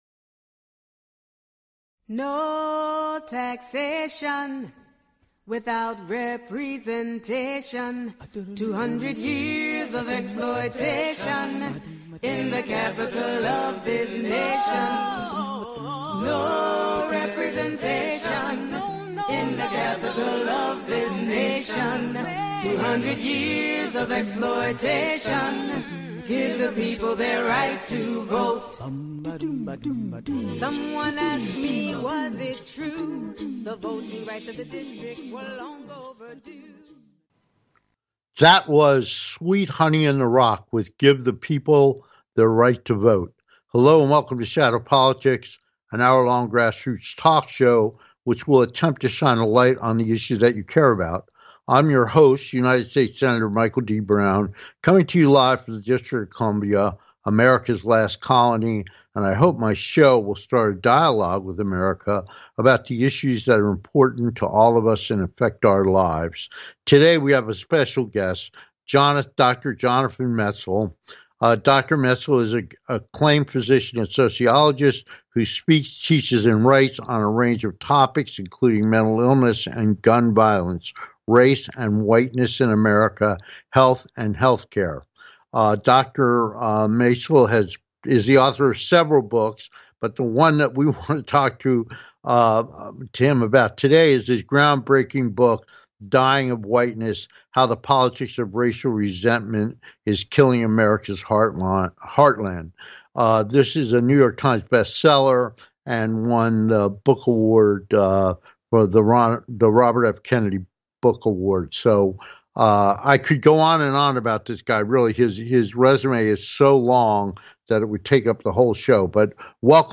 Guest, Dr. Jonathan Metzl author of Dying of Whiteness - How the Politics of Racial Resentment is Killing America’s Heartland